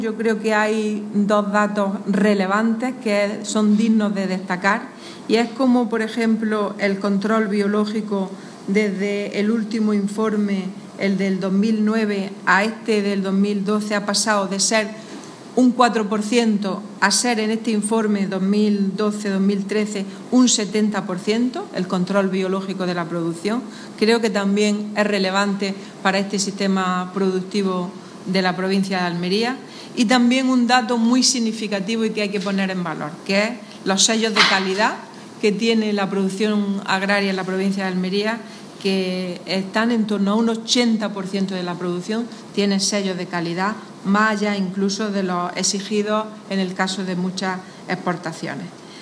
Declaraciones de Carmen Ortiz sobre Control Biológico y calidad diferenciada